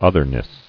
[oth·er·ness]